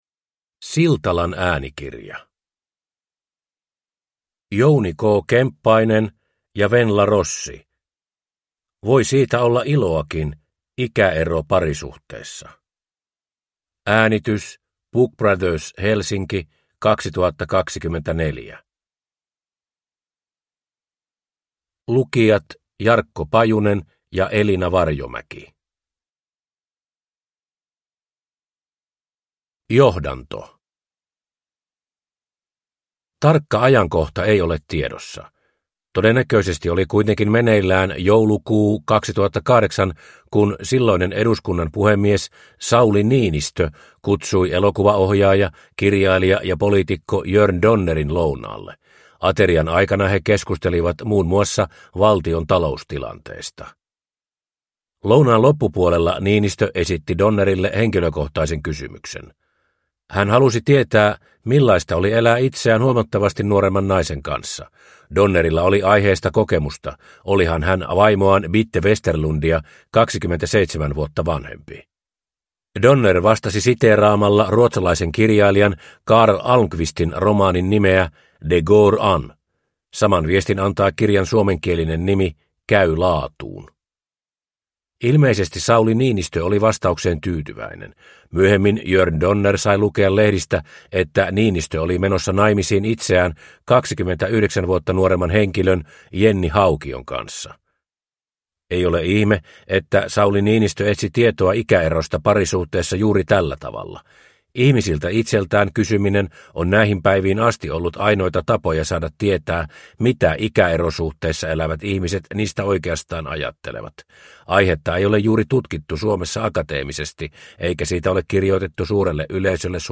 Voi siitä olla iloakin – Ljudbok